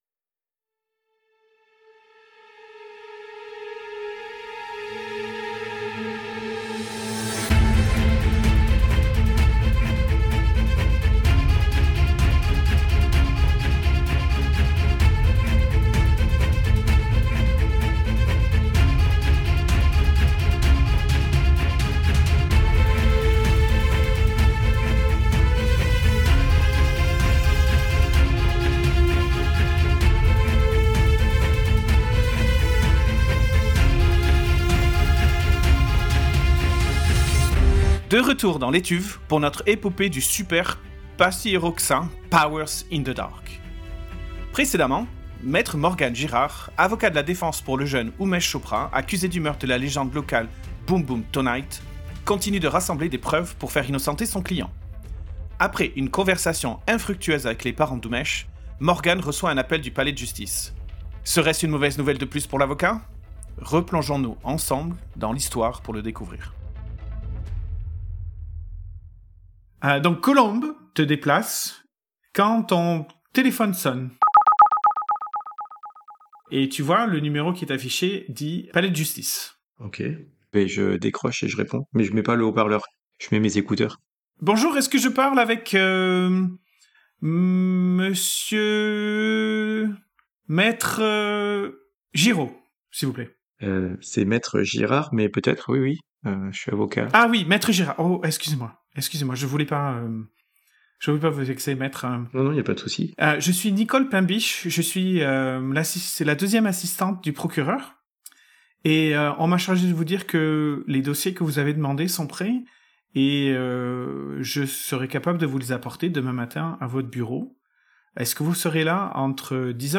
cell phone hang up.wav
ringing tone, answering, then hanging up